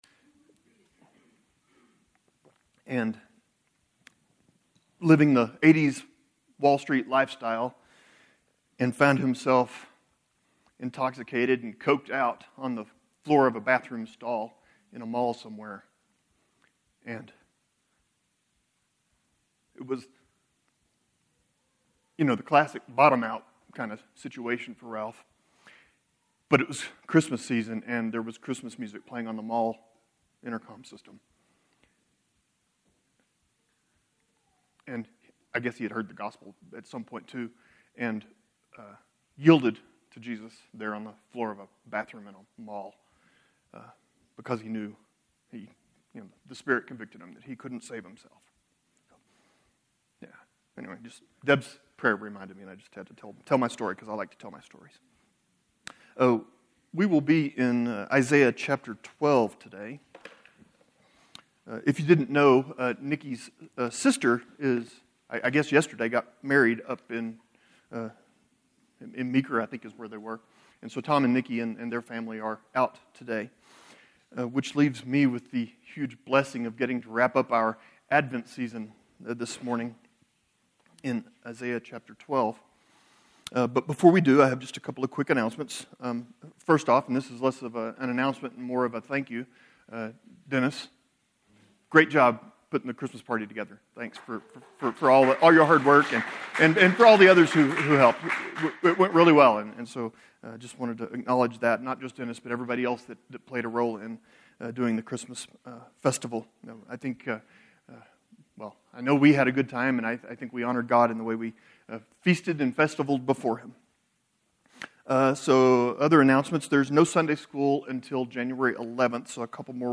Sermon Series: Advent 2025